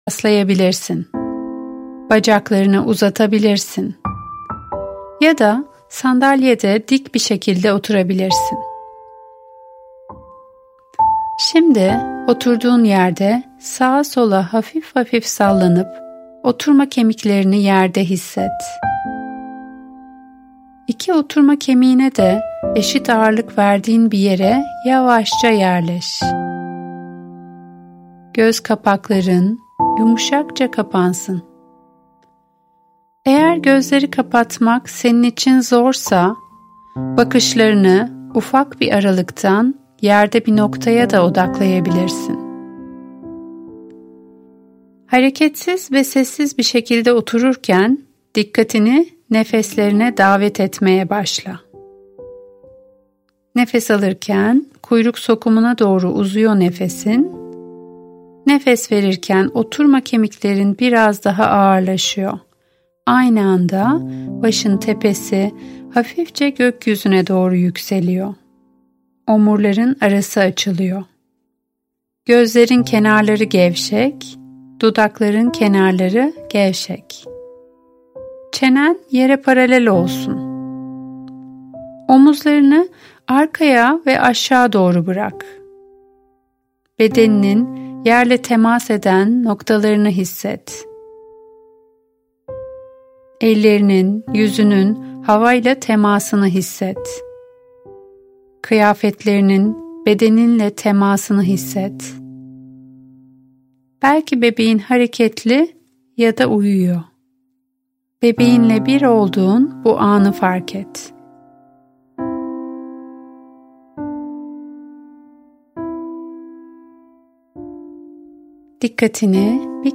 Hamileler için Nefes ve Meditasyon Çalışmaları – Beste: N.M.Zarkan (Median Müzik Edisyon)
Seslendiren